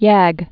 (yăg)